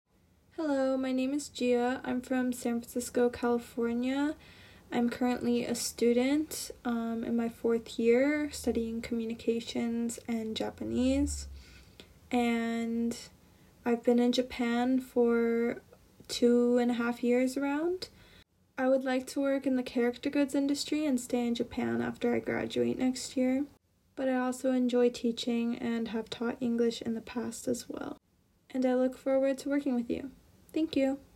先生の声